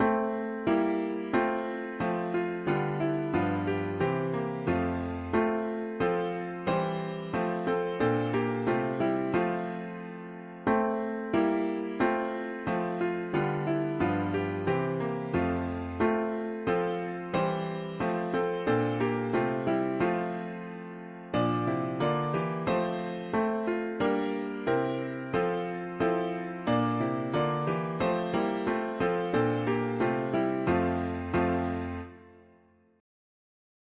h… english christian winter 4part
Key: F major Meter: 87.87.77